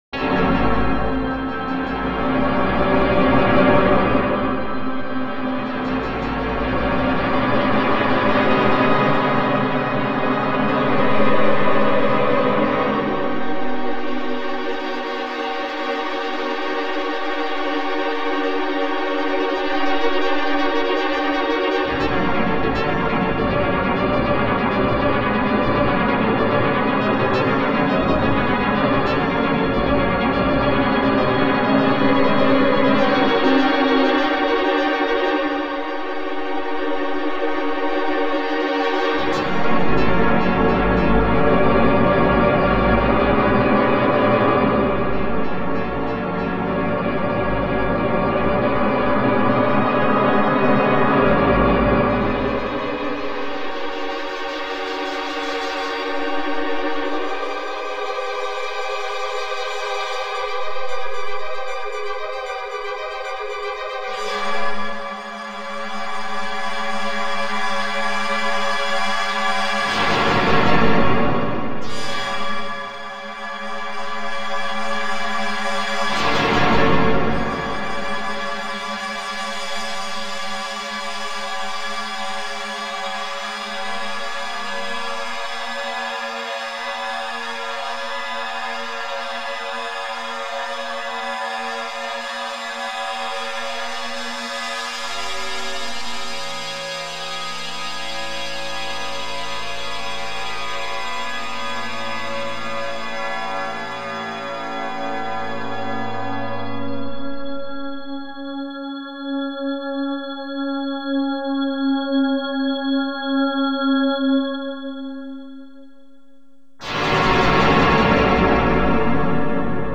Duration – 20”Recording – 2 channel version
symphonic textures
computer music
a somewhat dated sound